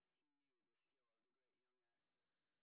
sp02_street_snr10.wav